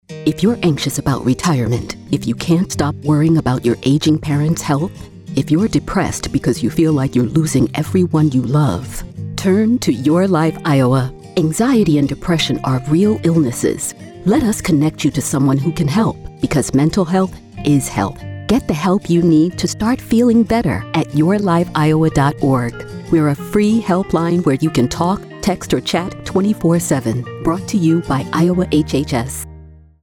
:30 Radio | Let Us Help You | (Female Voice 1)
Radio spot :30 Radio | Let Us Help You | (Female Voice 1) This campaign promotes older adults to reach out for help when experiencing anxiety, depression, and other mental health symptoms.
YLI Adult Mental Health Radio Spot Female.mp3